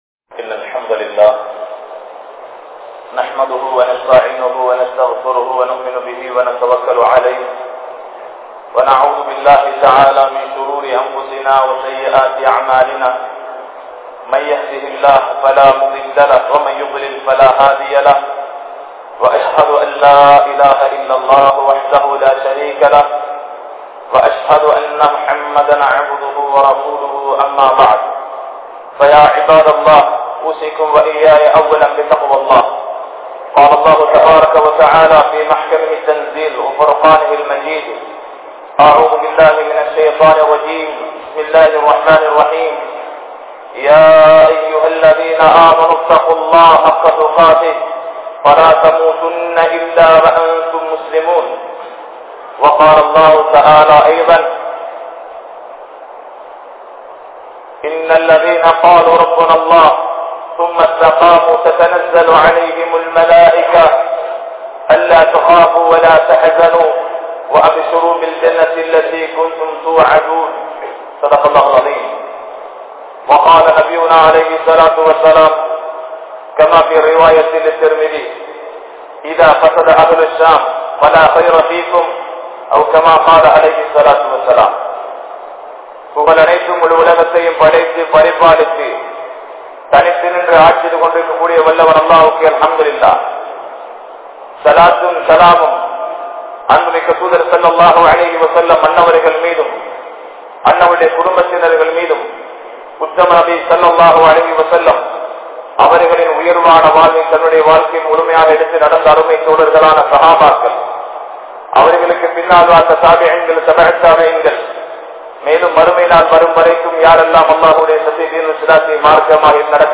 Palestine il Nadappathu Enna? (பலஸ்தீனில் நடப்பது என்ன?) | Audio Bayans | All Ceylon Muslim Youth Community | Addalaichenai